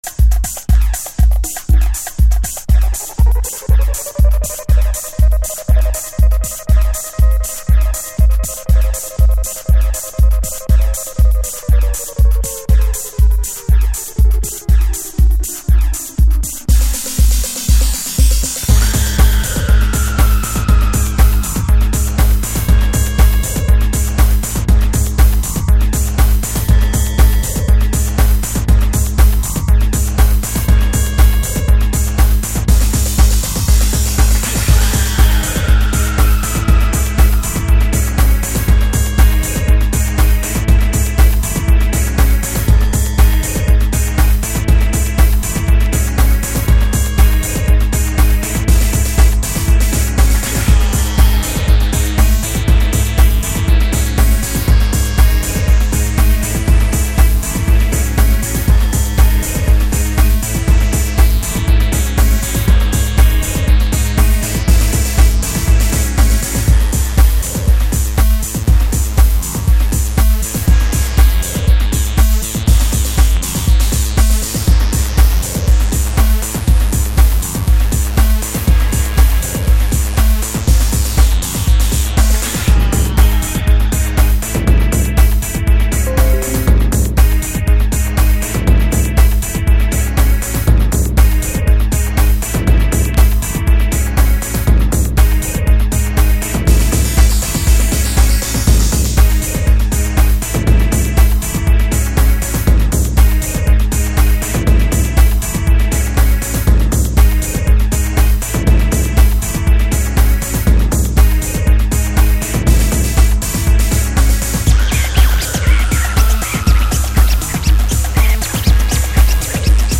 Early House / 90's Techno
しかし当時の潮流をしっかりと捕らえたブレイクビーツテクノを聴かせてくれます。